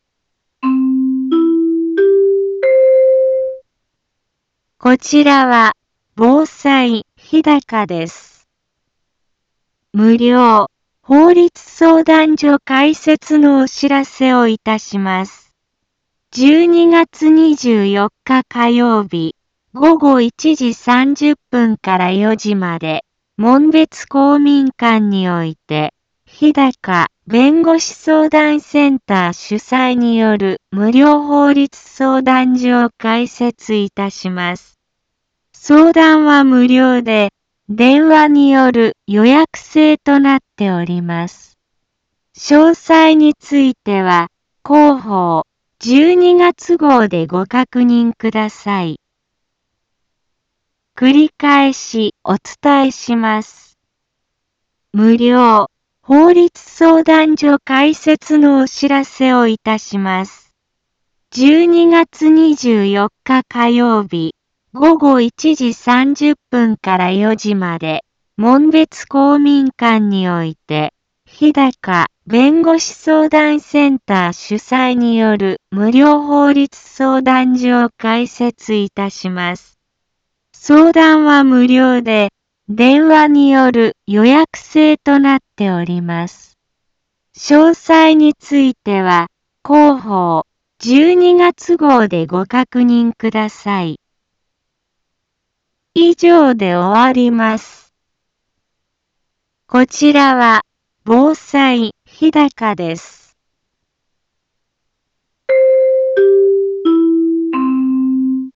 一般放送情報
Back Home 一般放送情報 音声放送 再生 一般放送情報 登録日時：2024-12-18 15:03:49 タイトル：無料法律相談会のお知らせ インフォメーション： こちらは、防災日高です。